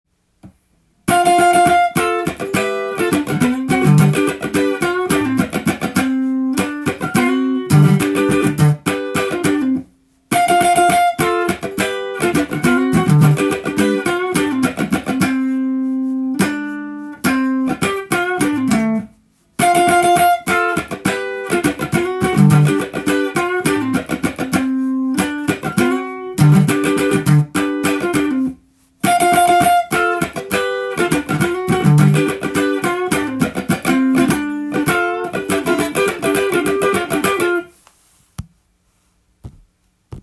譜面通りエレキギターで弾いてみました